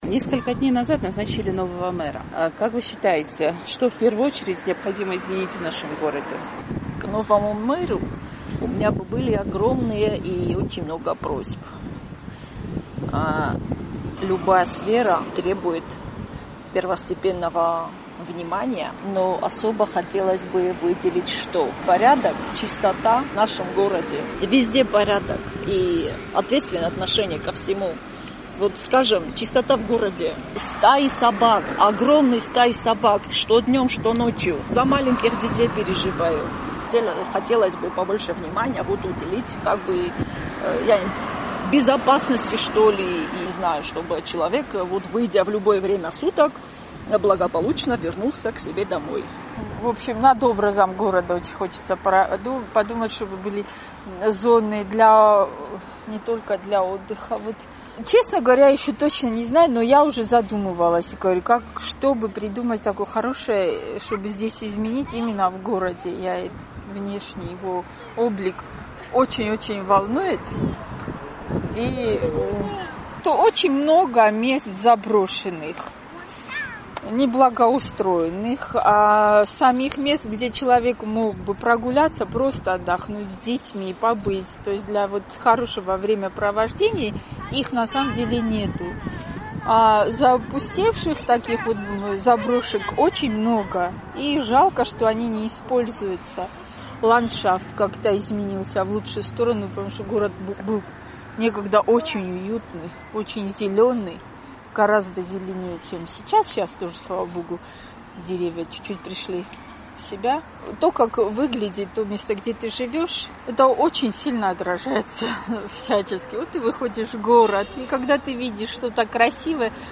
«Эхо Кавказа» спросило у цхинвальцев, что, по их мнению, в первую очередь следует изменить в городе.